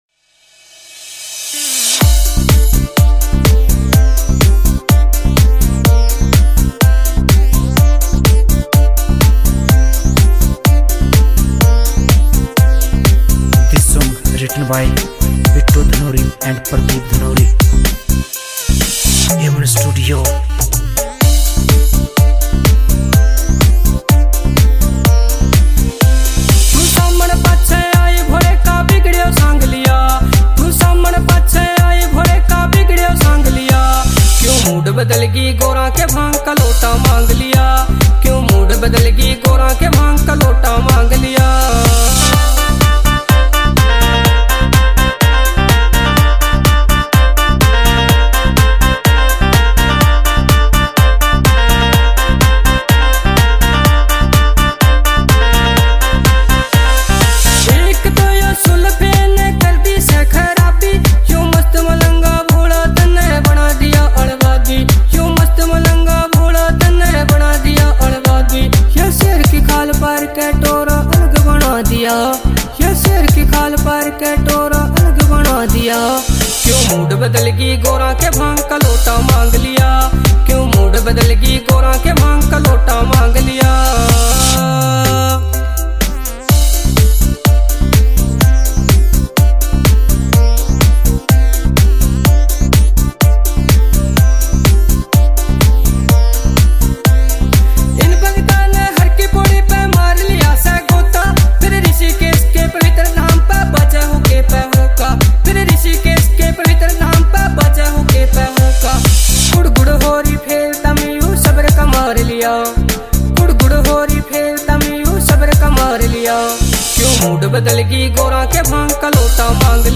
» Bhakti Songs